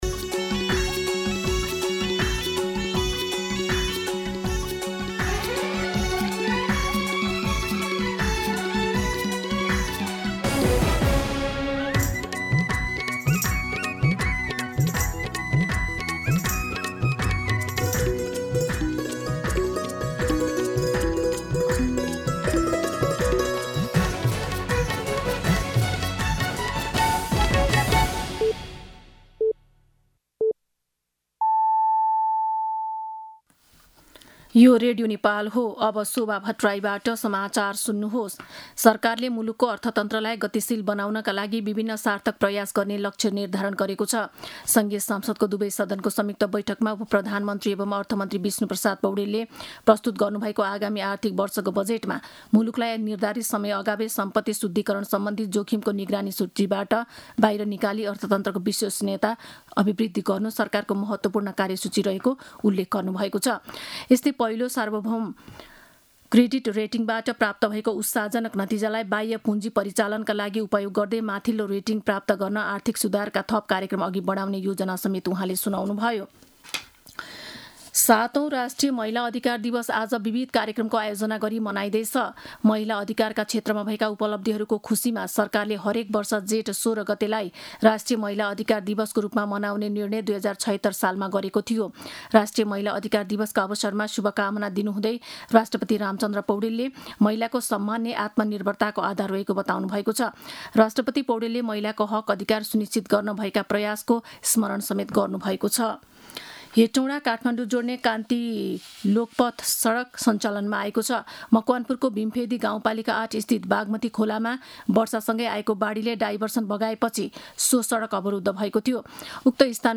दिउँसो १ बजेको नेपाली समाचार : १६ जेठ , २०८२